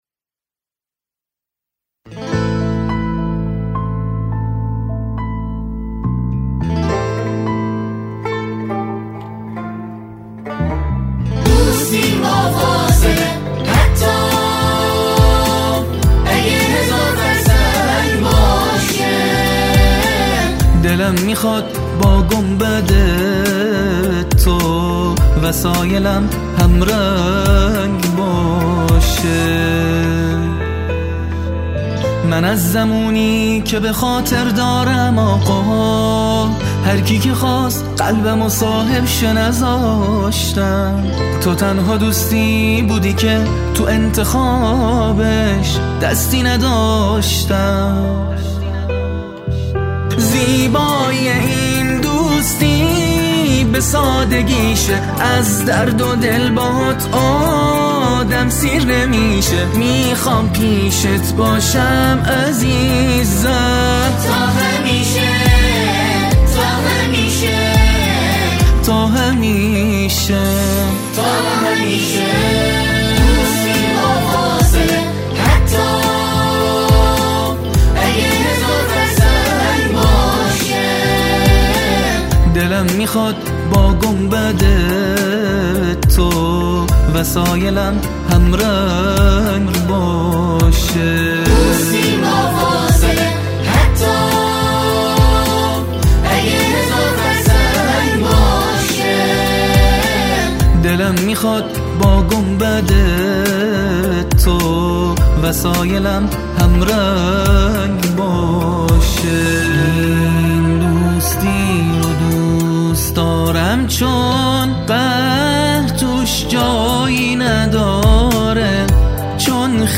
نماهنگ زیبای
ویژه سالروز میلاد امام رضا علیه السلام و دهه کرامت